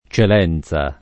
vai all'elenco alfabetico delle voci ingrandisci il carattere 100% rimpicciolisci il carattere stampa invia tramite posta elettronica codividi su Facebook Celenza [ © el $ n Z a ] top. — due comuni: C. sul Trigno (Abr.); C. Valfortone (Puglia) — sim. il cogn.